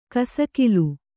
♪ kasakilu